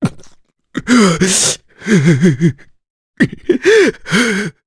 Gau-Vox_Sad_jp.wav